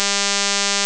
• Para um trem de Impulsos com frequência fundamental de 200Hz:
tremImpulsos.wav